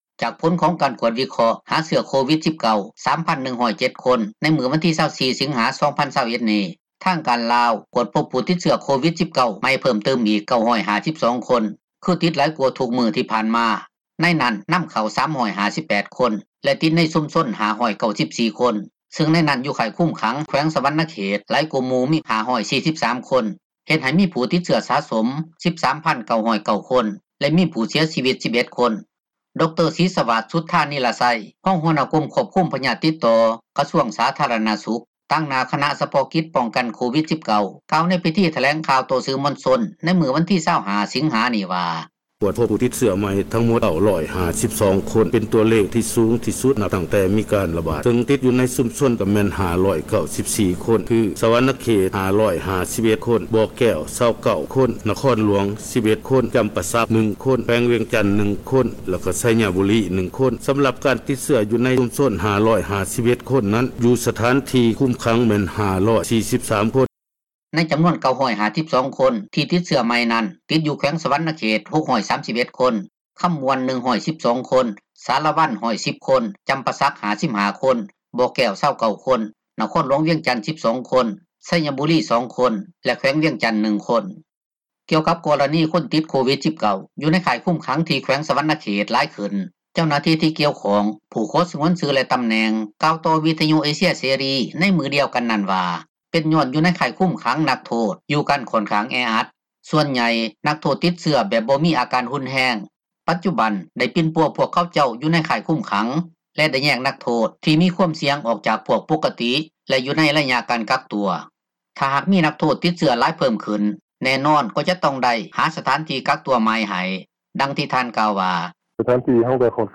ໃນນັ້ນ ນໍາເຂົ້າ 358 ຄົນ ແລະ ຕິດໃນຊຸມຊົນ 594 ຄົນ, ຊຶ່ງໃນນັ້ນ ຢູ່ຄ້າຍຄຸມຂັງ ແຂວງສວັນນະເຂດ ຫຼາຍກວ່າໝູ່ ມີ 543 ຄົນ, ເຮັດໃຫ້ມີ ຜູ້ຕິດເຊື້ອສະສົມ 13,909 ຄົນ ແລະ ມີຜູ້ເສັຍຊີວິດ 11 ຄົນ. ດຣ. ສີສະຫວາດ ສຸດທານິລະໄຊ, ຮອງຫົວໜ້າ ກົມຄວບຄຸມ ພະຍາດຕິດຕໍ່ ກະຊວງ ສາທາຣະນະສຸຂ, ຕາງໜ້າ ຄະນະສະເພາະກິດ ປ້ອງກັນ ໂຄວິດ-19 ກ່າວ ໃນພິທີ ຖແລງຂ່າວ ຕໍ່ສື່ມວນຊົນ ໃນມື້ວັນທີ 25 ສິງຫານີ້ວ່າ: